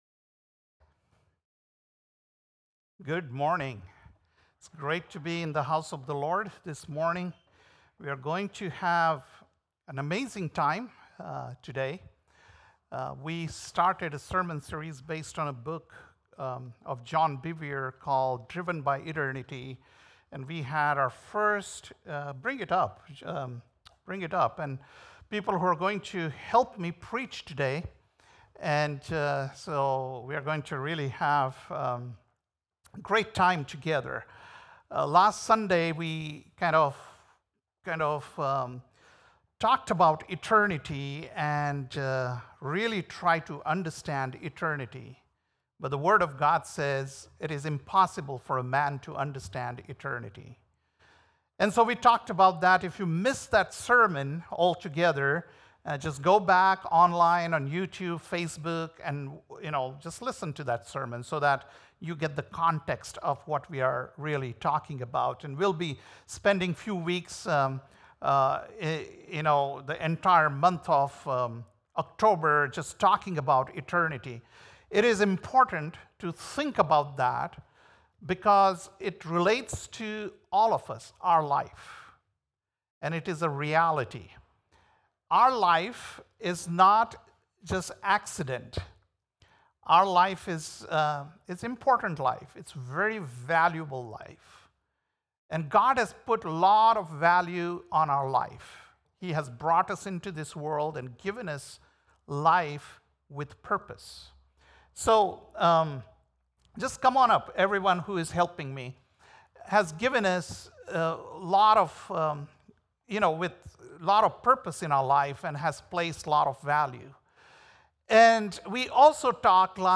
September 29th, 2024 - Sunday Service - Wasilla Lake Church